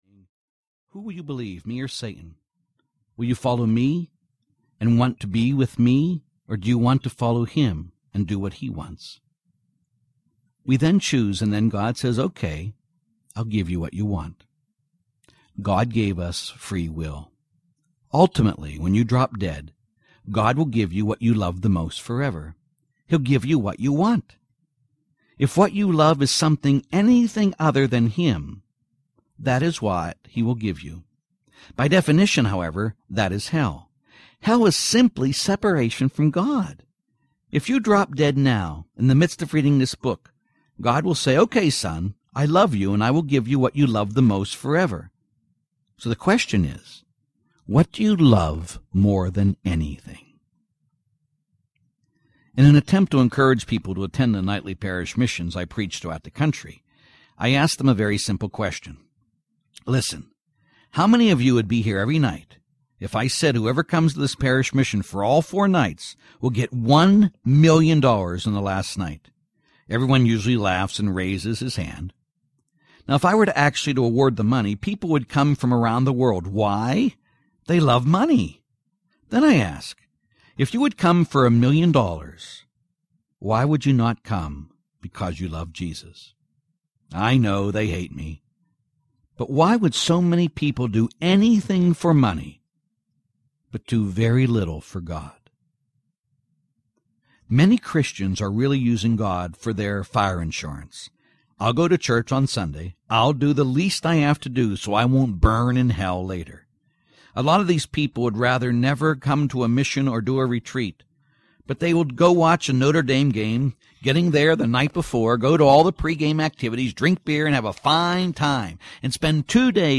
Be a Man! Audiobook
6 Hrs. – Unabridged